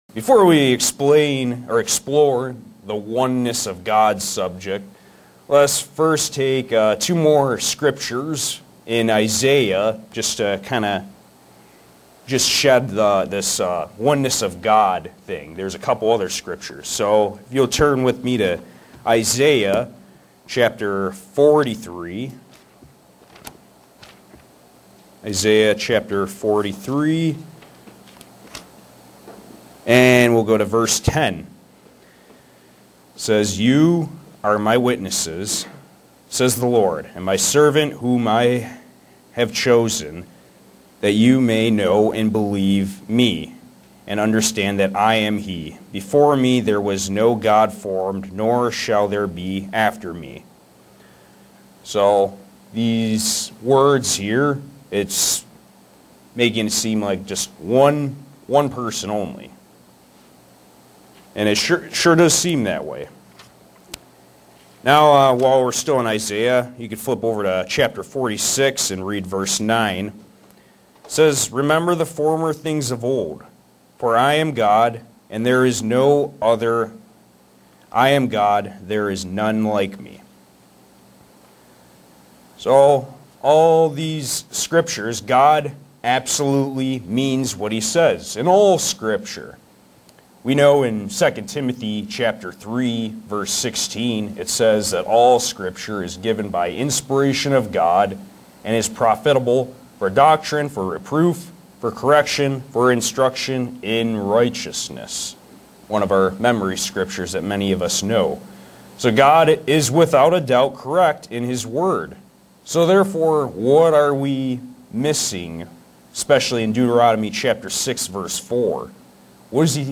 Print God the Father and Jesus Christ together as a family of one. sermons Studying the bible?
Given in Buffalo, NY